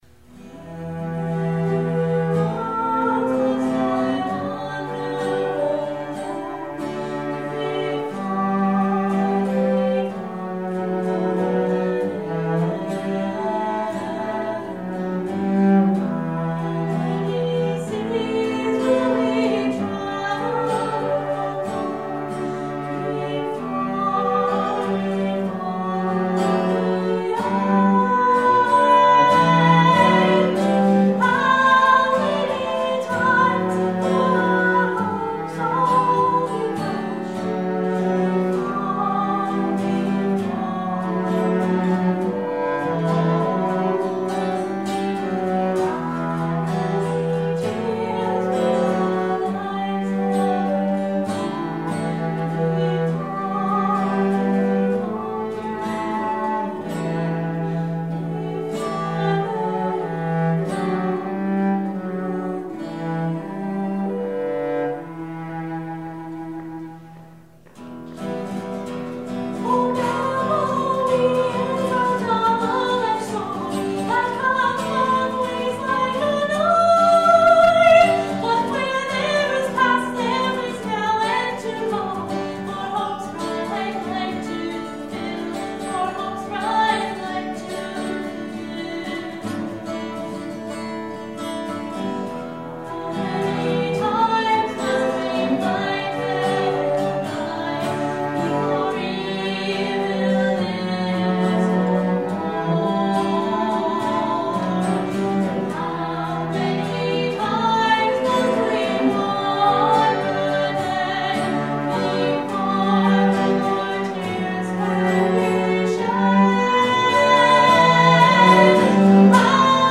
It was fun arranging the instruments and hopefully some day I’ll record a better live version.